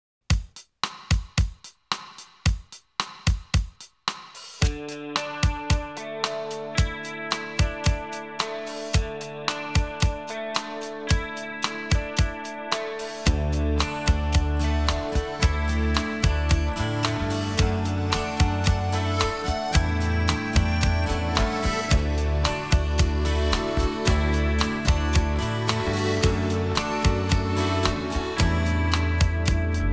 Genre: Musique francophone